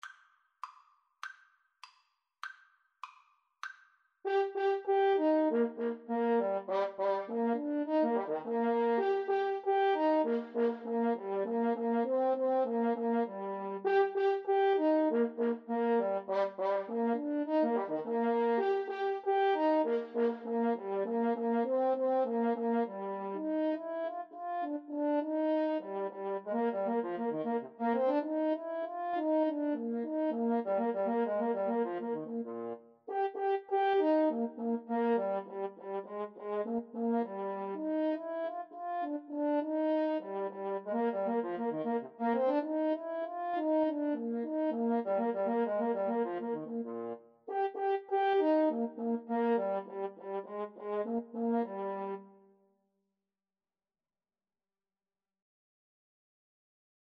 Allegro Molto (View more music marked Allegro)
2/4 (View more 2/4 Music)
French Horn Duet  (View more Easy French Horn Duet Music)
Classical (View more Classical French Horn Duet Music)